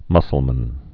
(mŭsəl-mən)